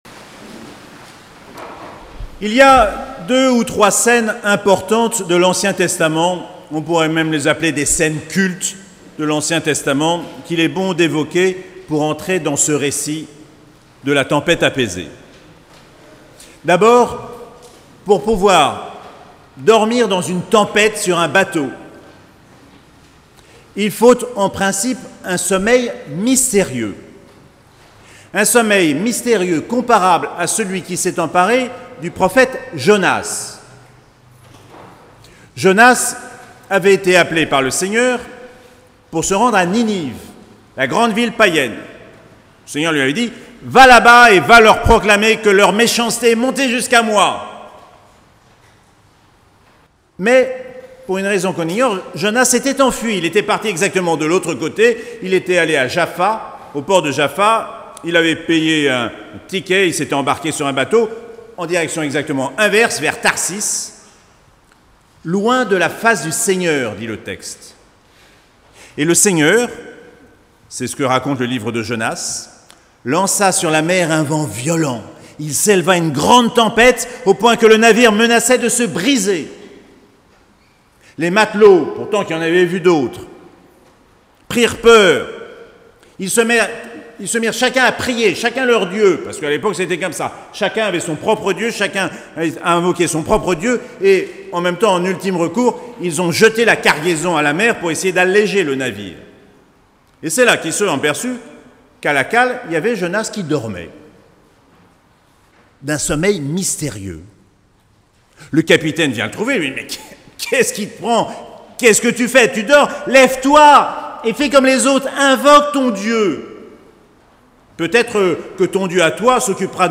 12ème Dimanche du Temps Ordinaire - 20 juin 2021